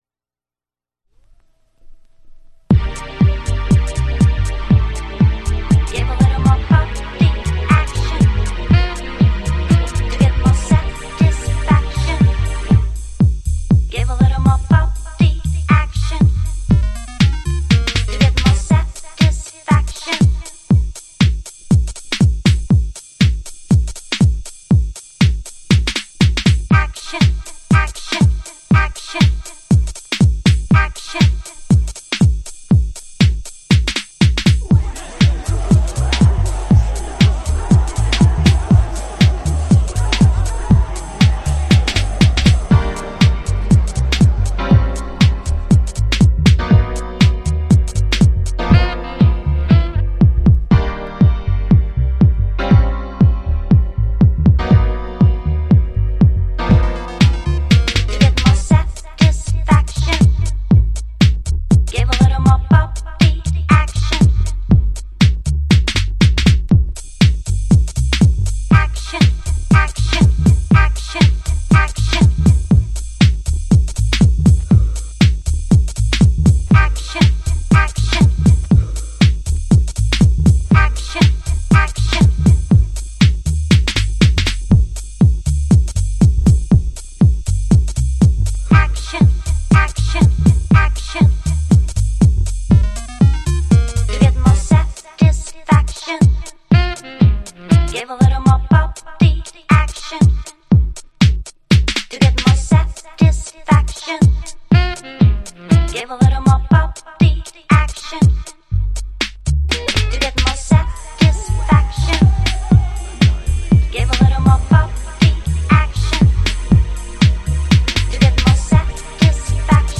TOP > Early House / 90's Techno > VARIOUS